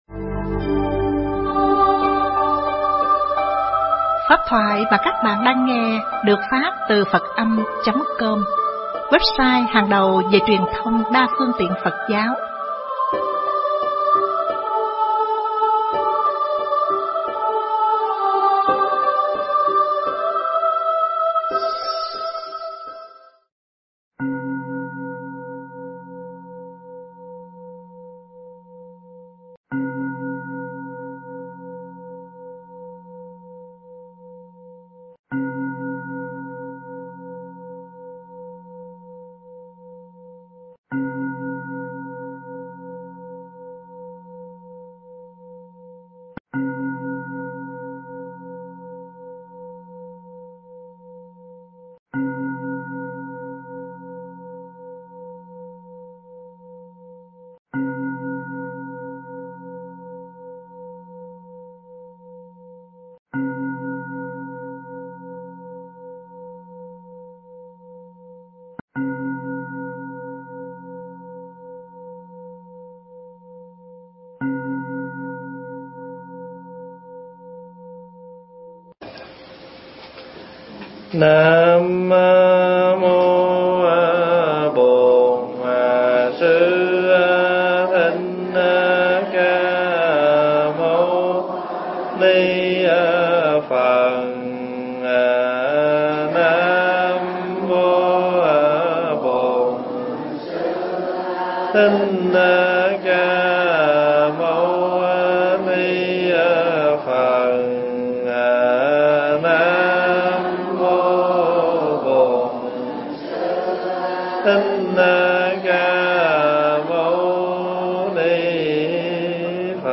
thuyết giảng tại Tu Viện Huyền Quang TX